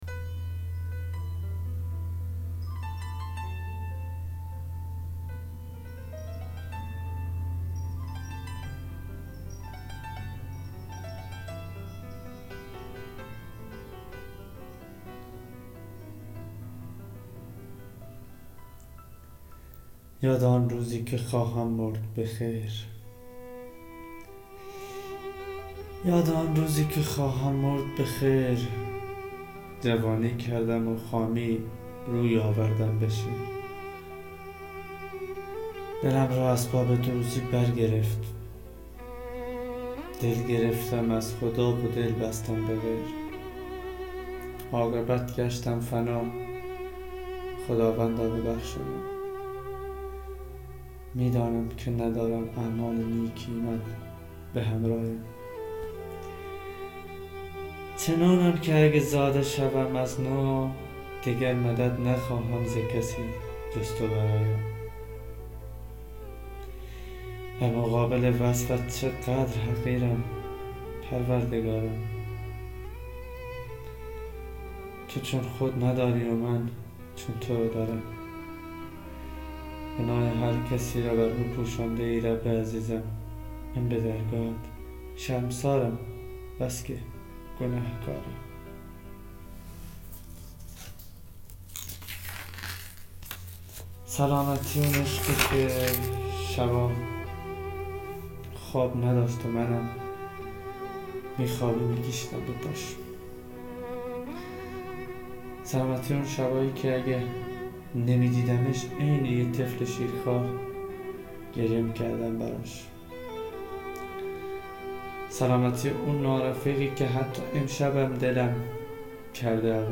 دکلمه شب نشینی وگریه